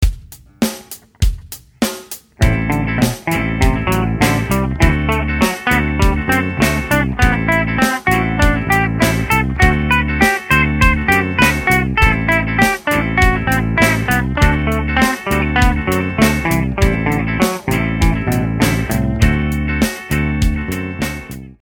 This exercise is similar to the skipping 3rds exercise except it skips in 4ths scale steps for a more angular sound.
dorian_a_4ths.mp3